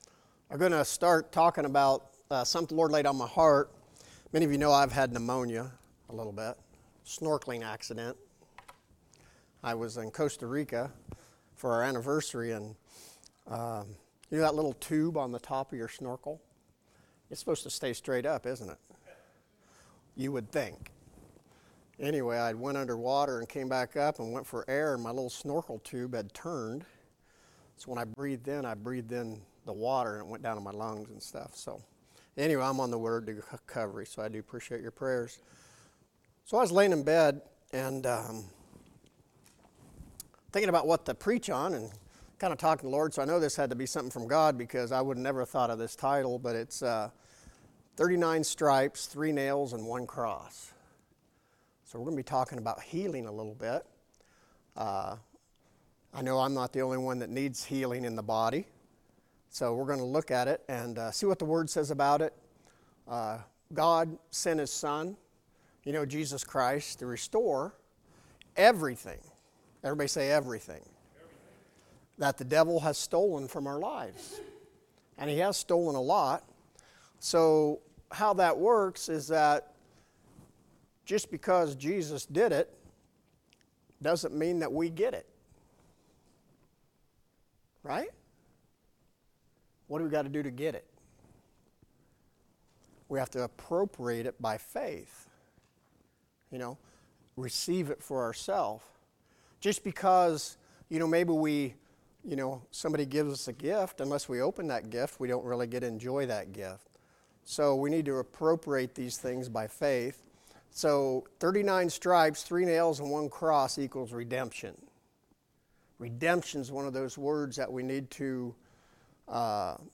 Sermons | River-Life Family Worship Center